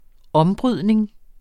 Udtale [ -ˌbʁyðˀneŋ ]